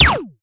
snd_ghost.ogg